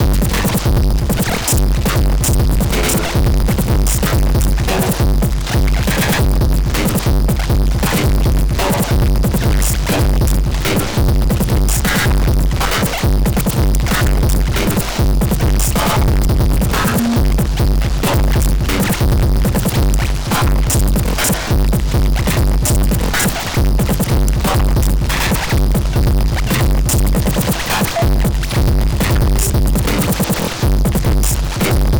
Loop-Noise.wav